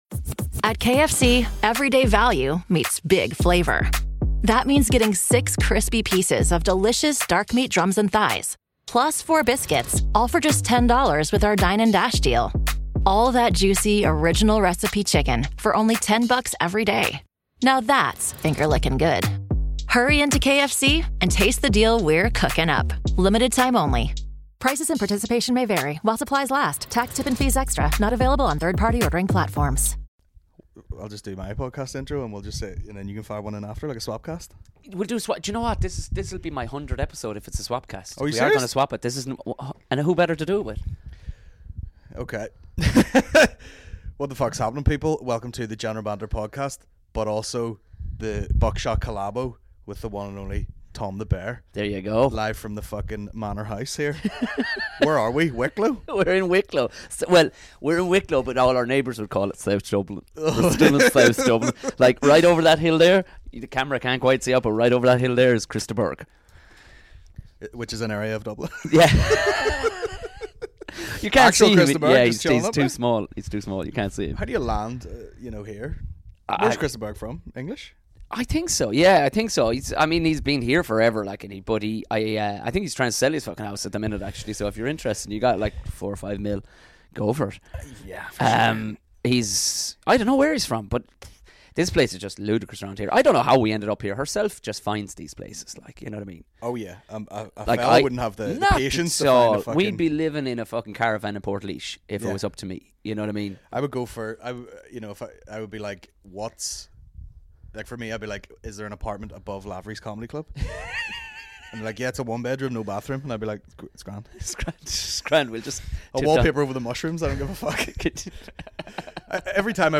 General Banter Podcast General Banter Podcast Comedy 4.8 • 1.1K Ratings 🗓 22 February 2019 ⏱ 78 minutes 🔗 Recording | iTunes | RSS 🧾 Download transcript Summary This week - The Poteen Dumps.